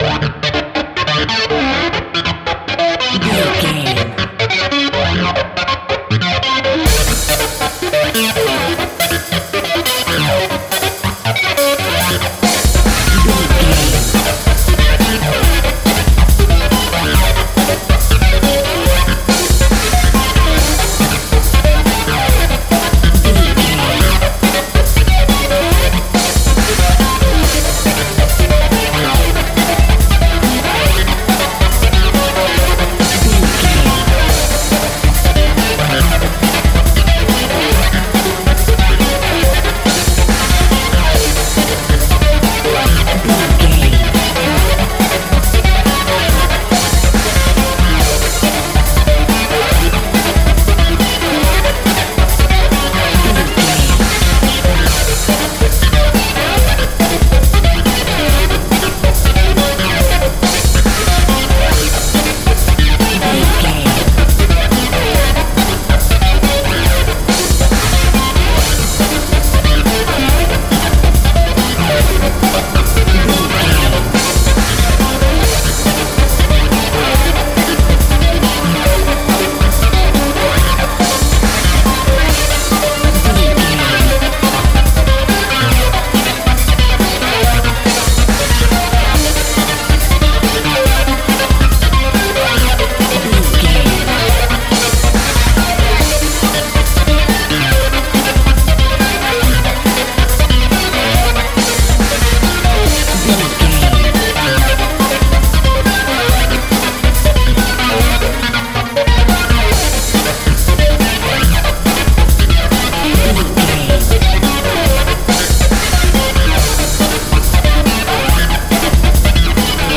Fast paced
Atonal
Fast
aggressive
powerful
dark
driving
energetic
intense
drums
synthesiser
power rock
electronic
synth lead
synth bass